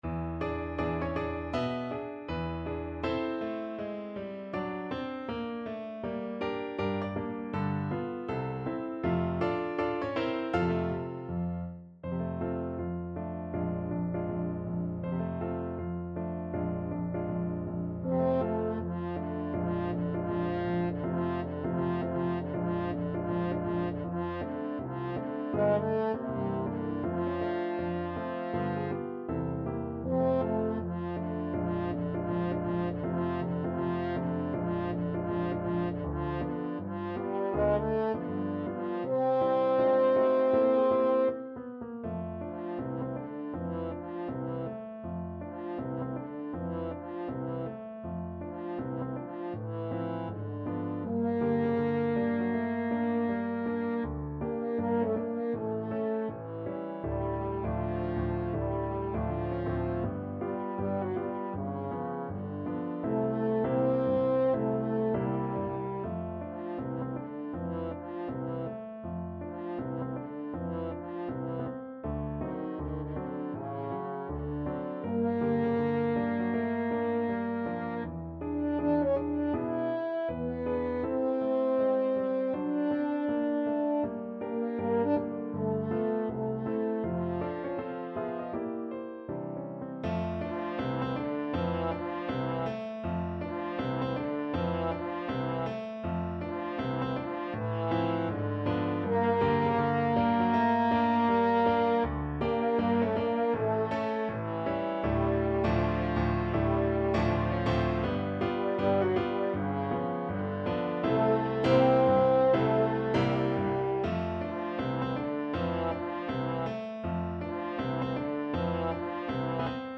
Jazz
~ = 160 Moderato